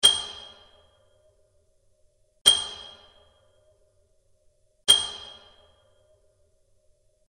关于石头砸晕音效的PPT演示合集_风云办公